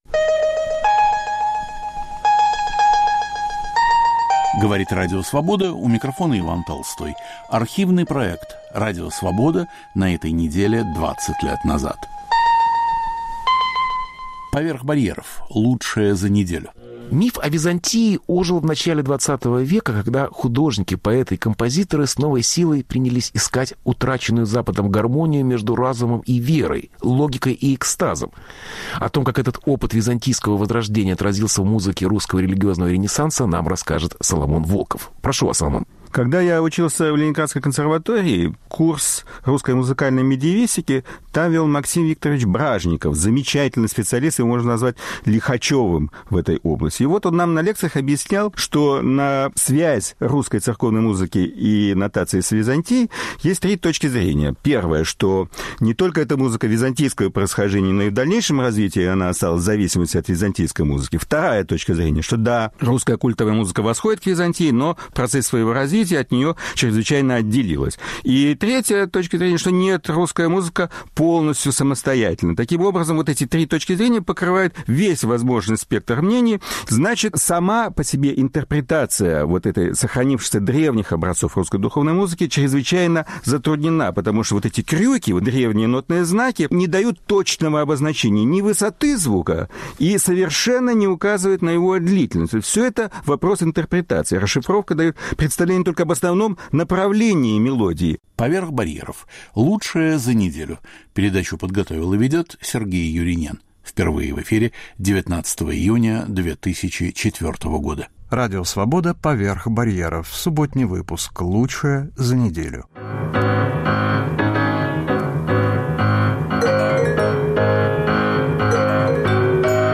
Беседа с Марией Кодама, вдовой Борхеса.